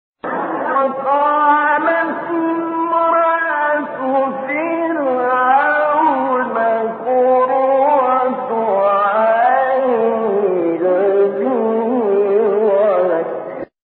6 فراز صوتی در مقام «کُرد»
گروه شبکه اجتماعی: فرازهایی صوتی از تلاوت شش قاری برجسته مصری که در مقام کُرد اجرا شده‌اند، ارائه می‌شود.